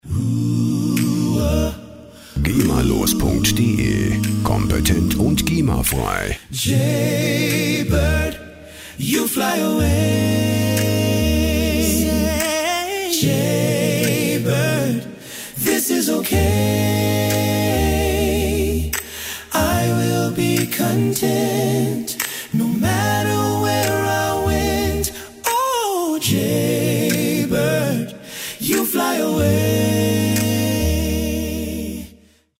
Instrument: Stimmen
Tempo: 73 bpm